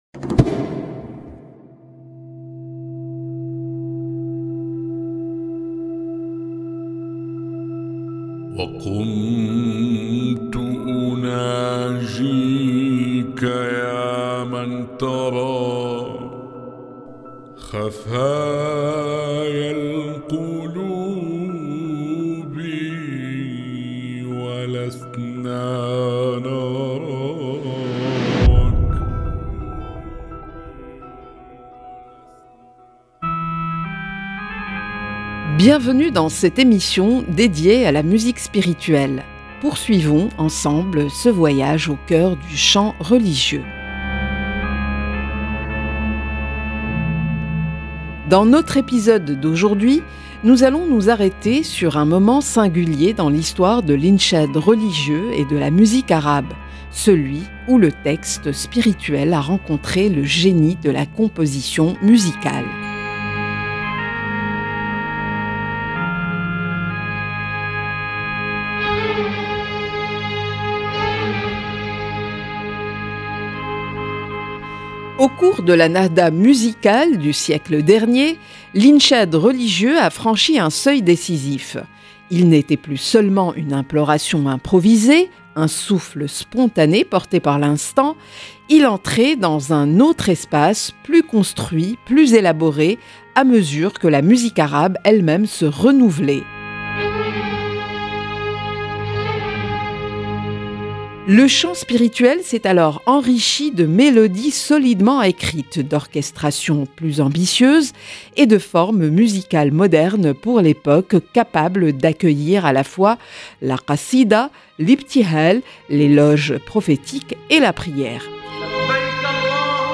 présentée en français